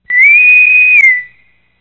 Download Star Trek sound effect for free.